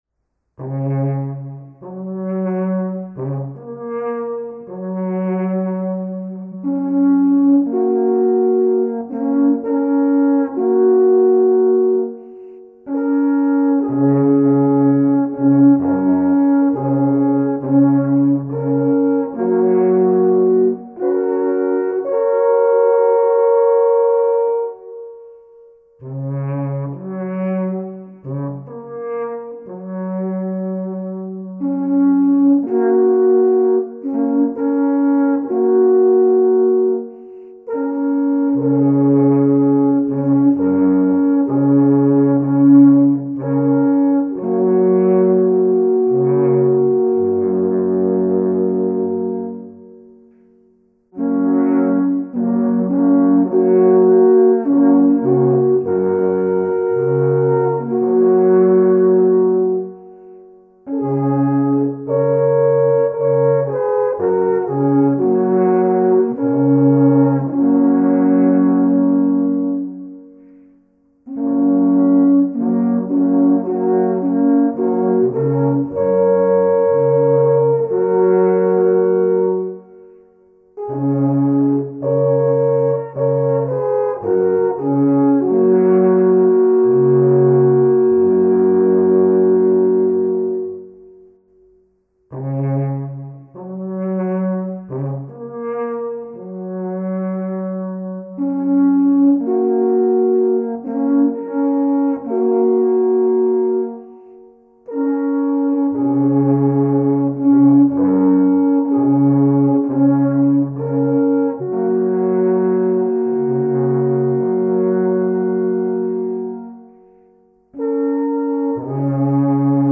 Traditionelle und moderne Alphornmusik